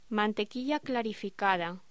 Locución: Mantequilla clarificada
voz